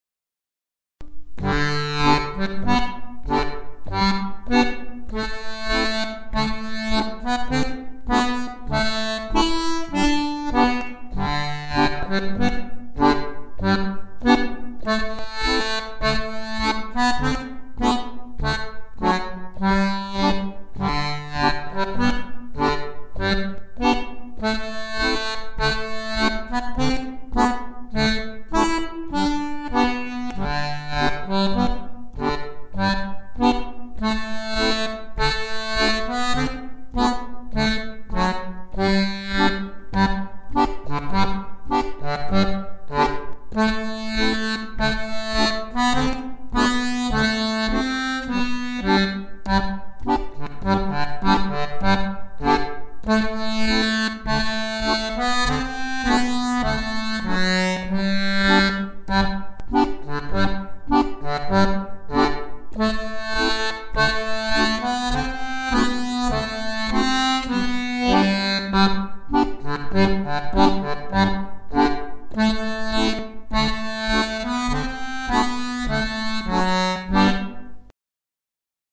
Tabs for chromatic accordion
La semaine des 7 lundis* Scottish à 2 voix Voix 1
Voix 2 Lent